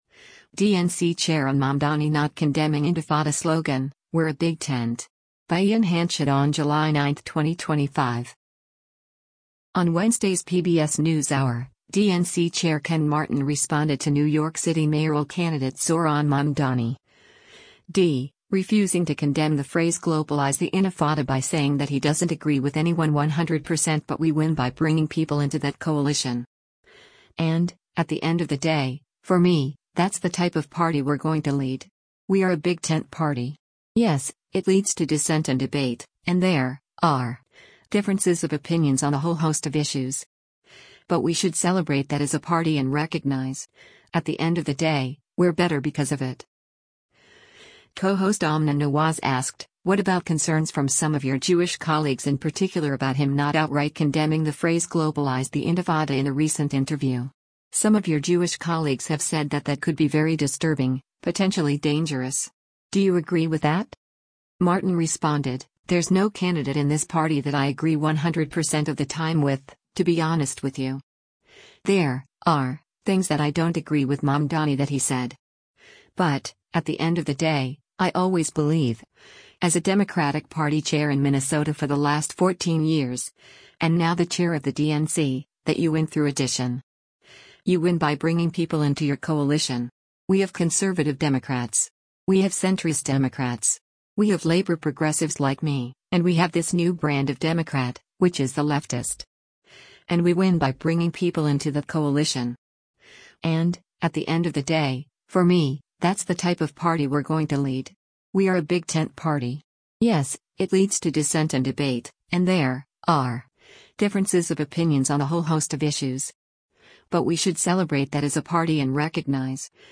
Co-host Amna Nawaz asked, “What about concerns from some of your Jewish colleagues in particular about him not outright condemning the phrase globalize the intifada in a recent interview? Some of your Jewish colleagues have said that that could be very disturbing, potentially dangerous. Do you agree with that?”